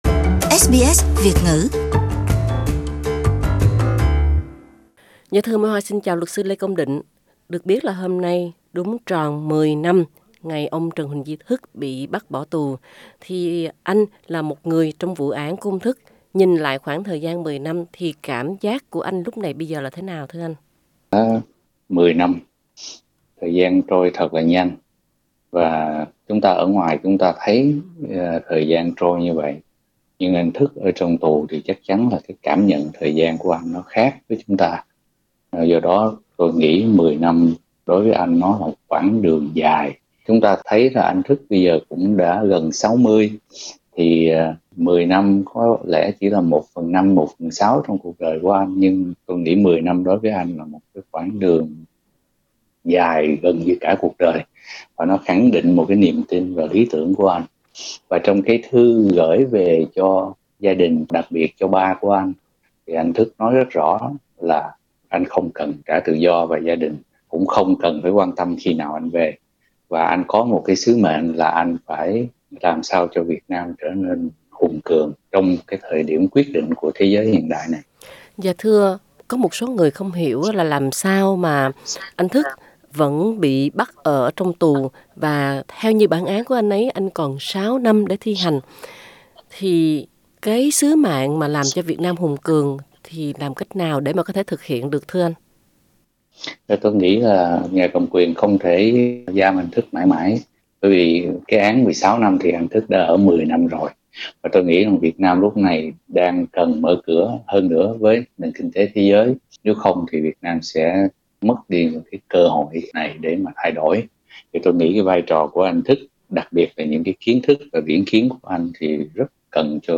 cuộc trò chuyện